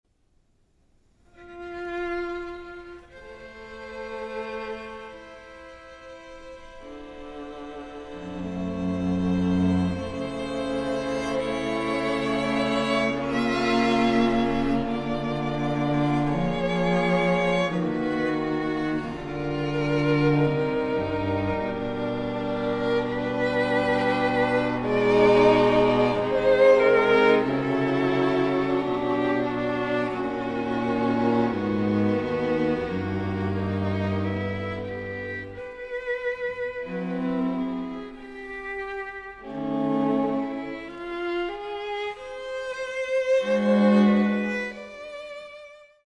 inventively contrapuntal, lyrical and energetic in turns
The acoustic is apt and not inflated
with good balance, accurate intonation and rhythms